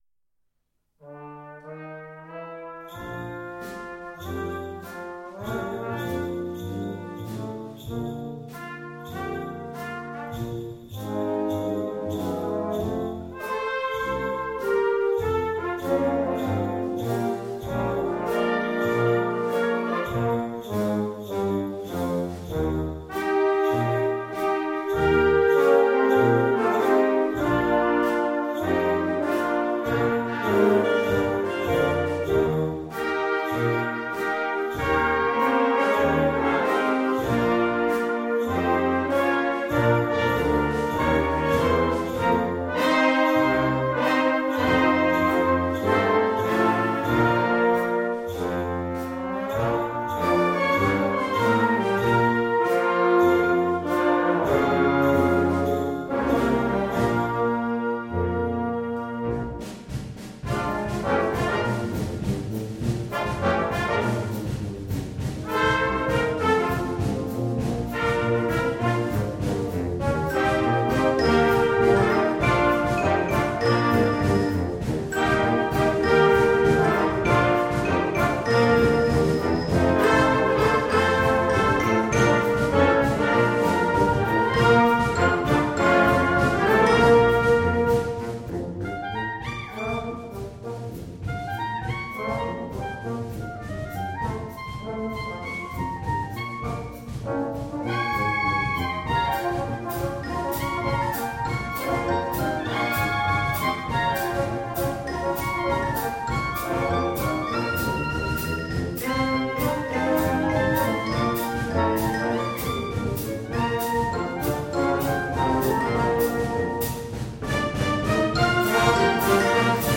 Gattung: Weihnachtsmusik für Blasorchester
Besetzung: Blasorchester